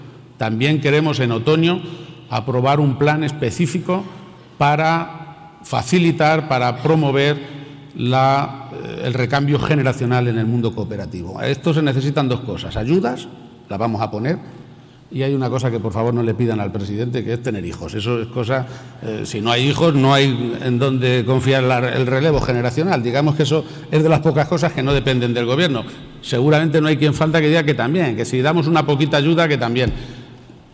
El jefe del Ejecutivo regional hacía estas declaraciones en el marco de la V edición de los Premios Cooperativos que entrega Cooperativas Agro-Alimentarias de Castilla-La Mancha y que han tenido lugar en el Paraninfo de la UCLM, en el campus de Ciudad Real.